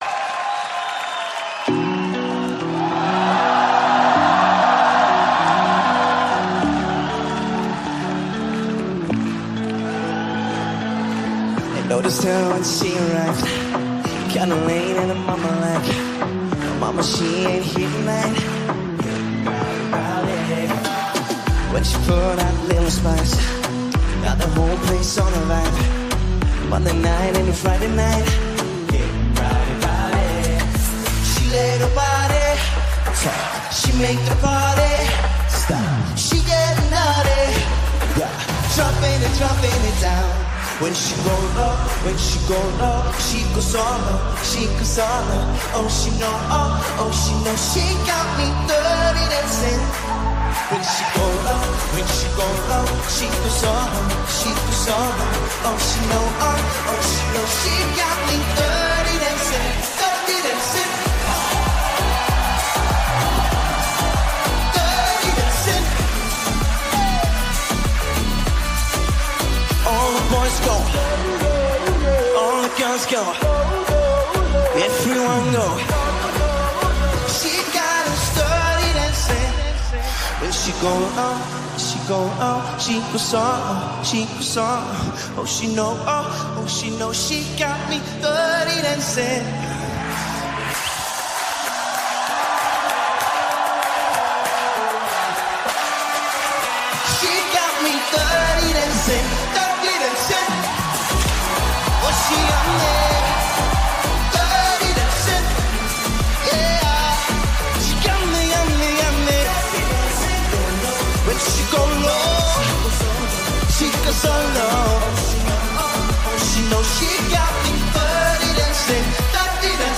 BPM97
Audio QualityPerfect (High Quality)
Comments*The real BPM of this song is 97.035